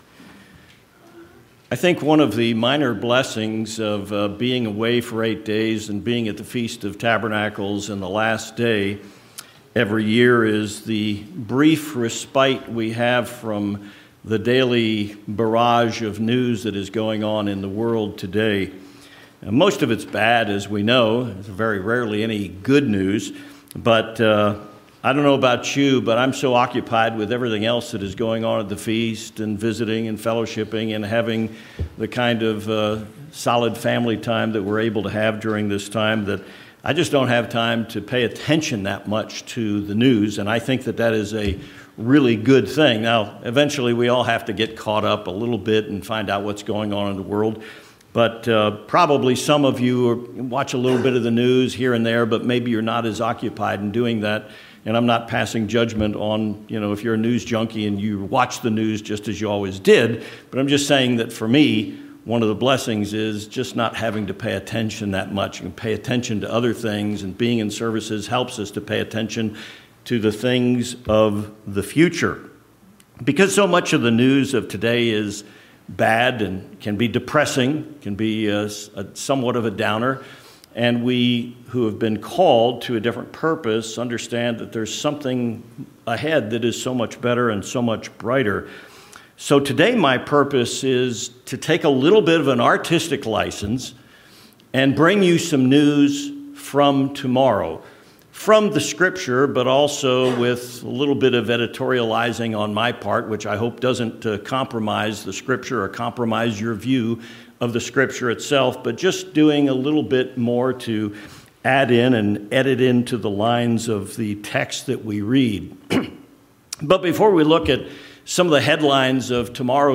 This sermon investigates the fulfillment of prophecy during the beginning of the Millennium and potential stories we may read about in the future.